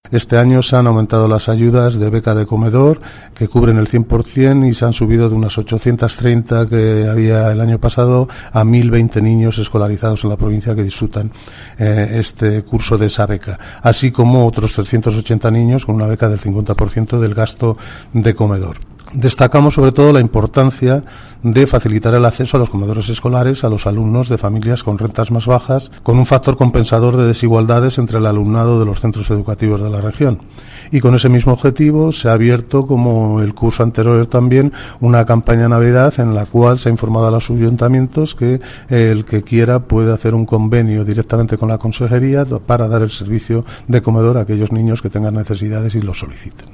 El director provincial de Educación, Cultura y Deportes habla sobre becas de comedor en la provincia de Guadalajara